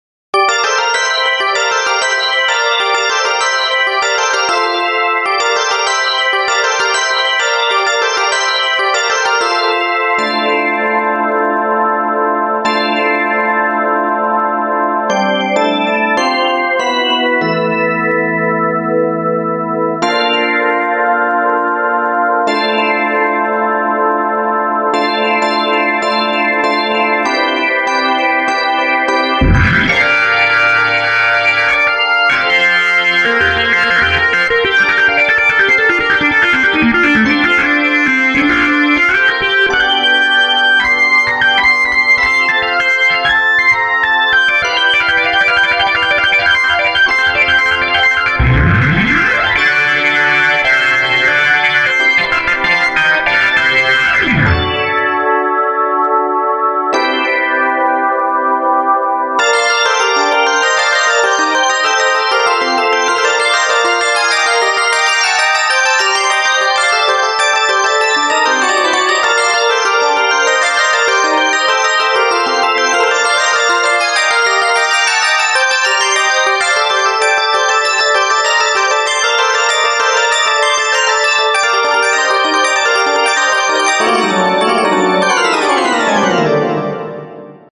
●5thPercussion●VARockOrgan